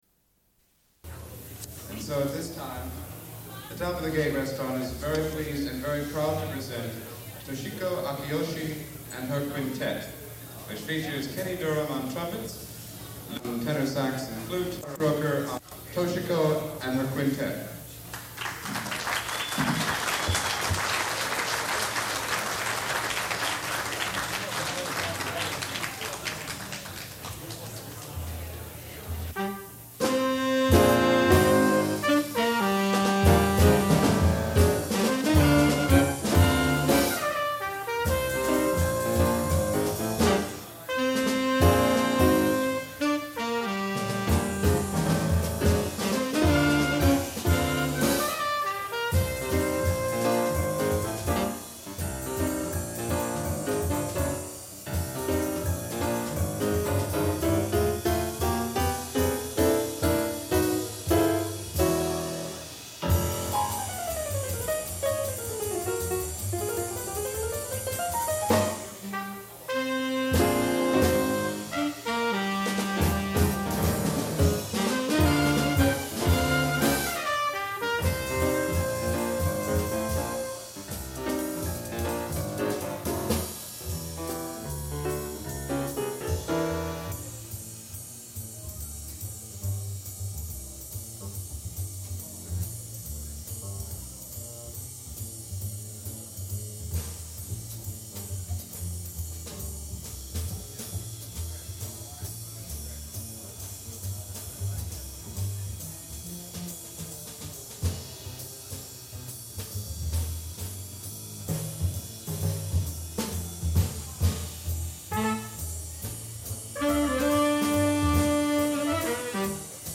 Une cassette audio, face A00:46:50